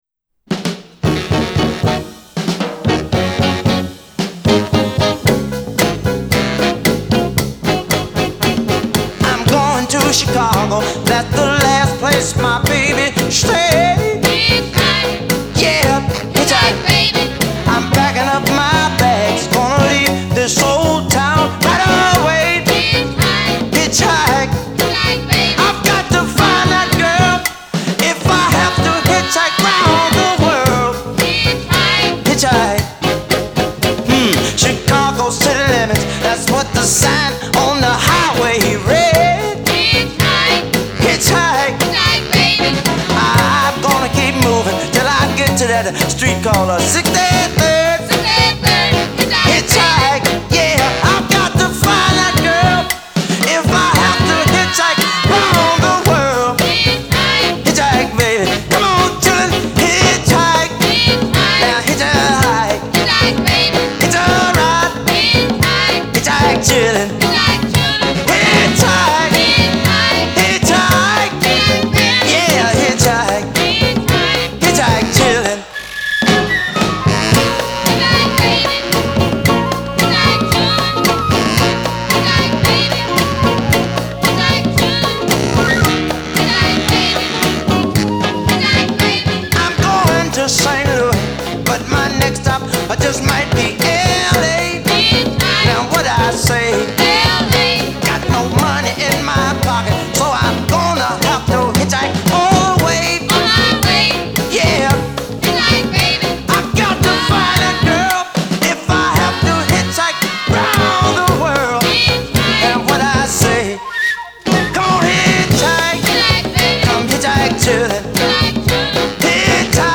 Mono Single